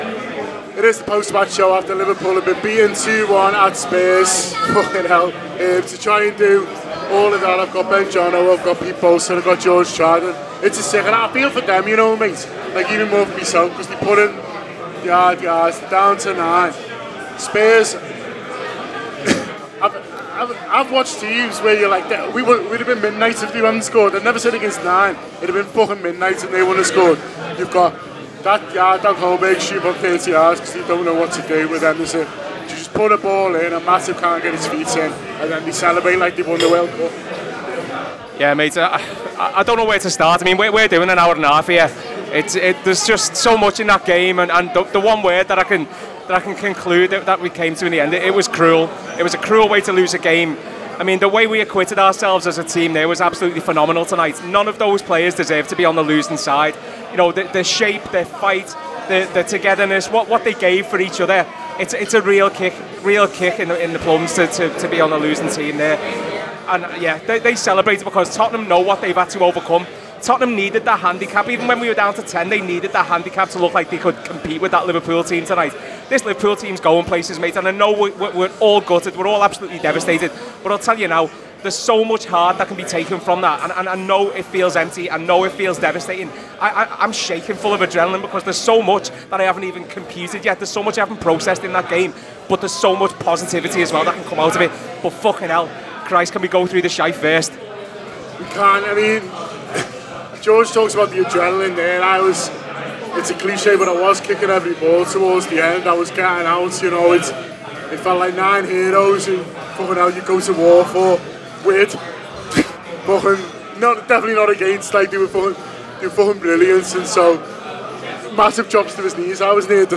Below is a clip from the show – subscribe for more Tottenham Hotspur 2 Liverpool 1 reaction…